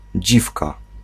Ääntäminen
IPA: /ˈd͡ʑifka/